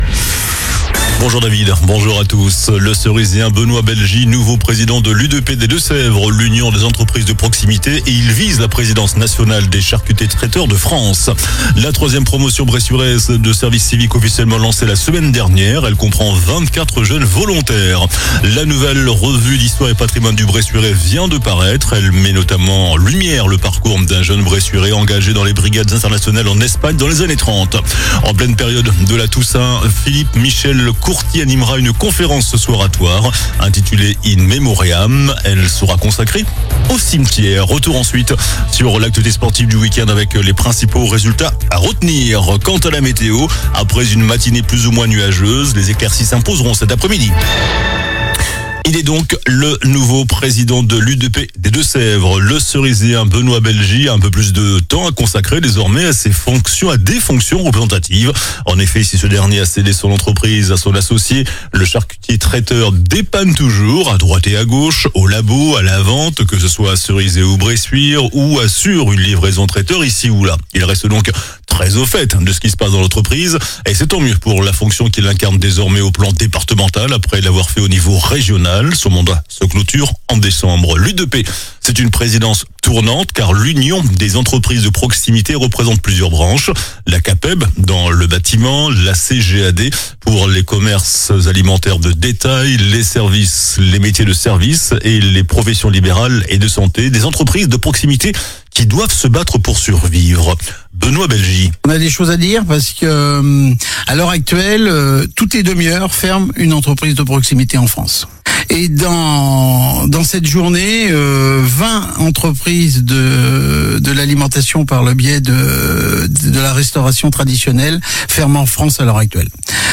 JOURNAL DU LUNDI 03 NOVEMBRE ( MIDI )